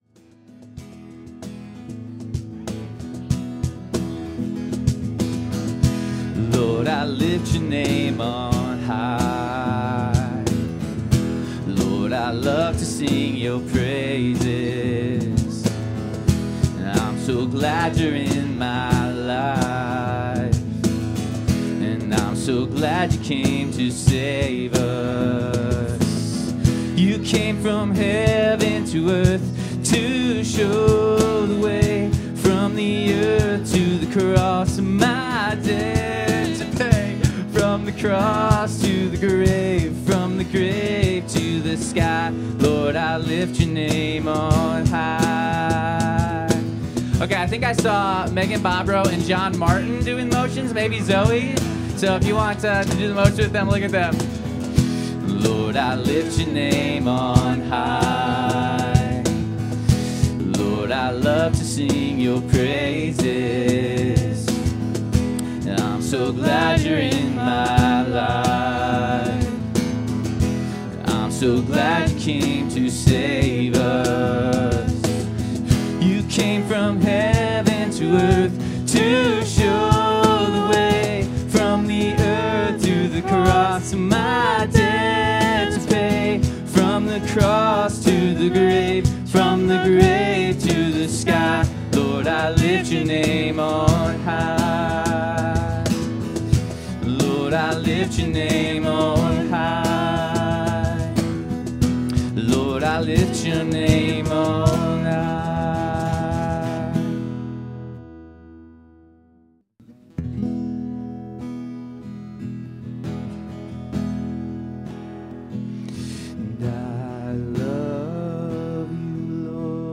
Worship 2025-09-07